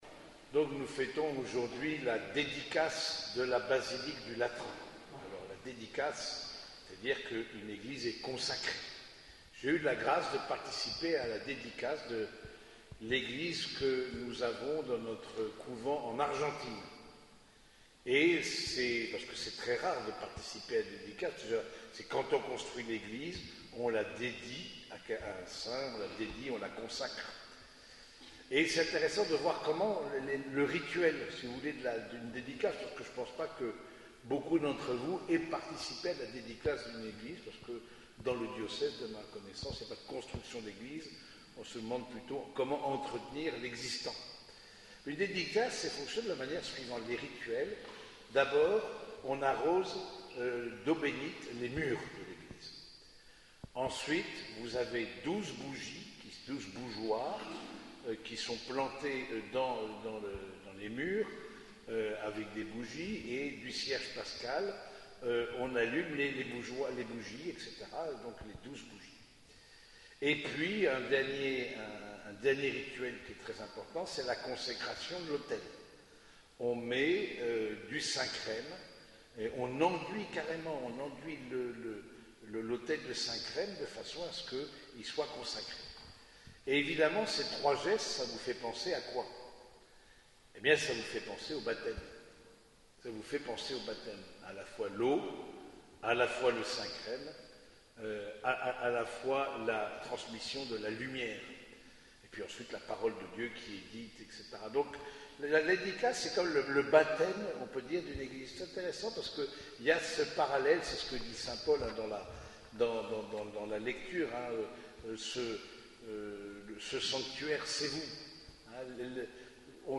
Homélie de la fête de la dédicace de la basilique du Latran
Cette homélie a été prononcée au cours de la messe dominicale célébrée à l’église Saint-Germain de Compiègne.